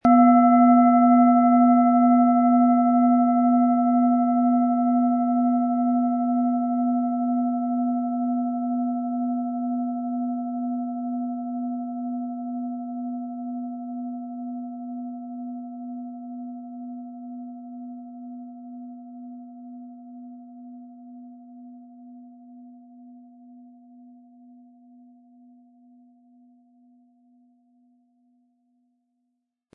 • Mittlerer Ton: Wasser
Um den Original-Klang genau dieser Schale zu hören, lassen Sie bitte den hinterlegten Sound abspielen.
Spielen Sie die Lilith mit dem beigelegten Klöppel sanft an, sie wird es Ihnen mit wohltuenden Klängen danken.
MaterialBronze